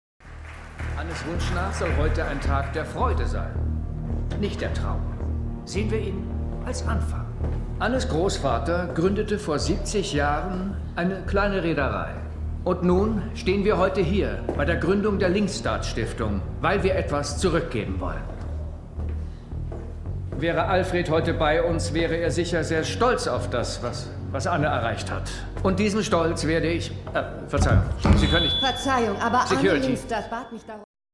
synchron-the woman in the cabin 10-nr.2
Philipp Moog ist die Feststimme von Ewan McGregor, Owen Wilson, Guy Pearce, Neil Patrick Harris und eine der Feststimmen von Orlando Bloom.